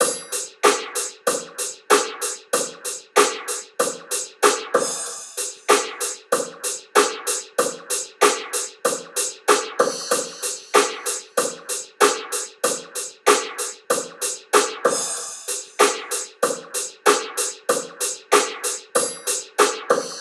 You can also apply other processing to the IR’s to create more complex and interesting effects, here is an example where the same IR has been passed through a phaser, envelope filter and finally high passed:
ir-example-drum-loop-100-wet-bathroom-1-processed-3.wav